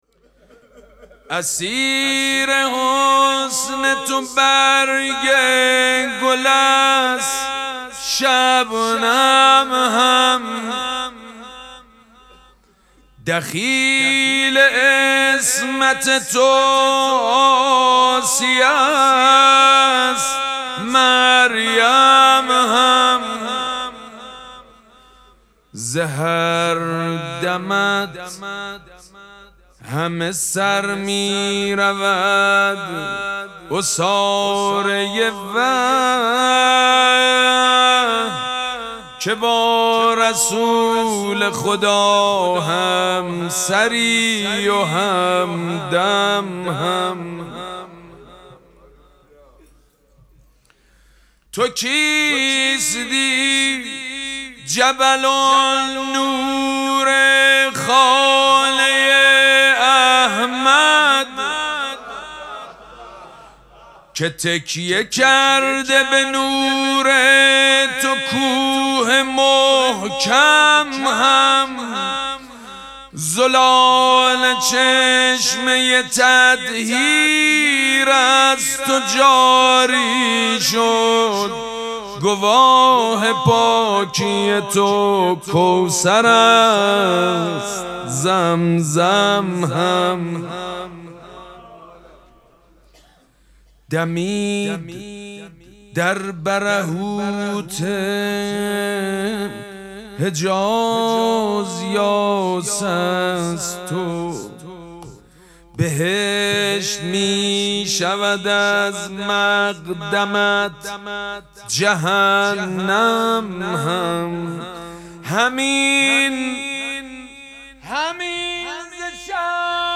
مراسم مناجات شب دهم ماه مبارک رمضان دوشنبه ۲۰ اسفند ماه ۱۴۰۳ | ۹ رمضان ۱۴۴۶ حسینیه ریحانه الحسین سلام الله علیها
سبک اثــر مدح مداح حاج سید مجید بنی فاطمه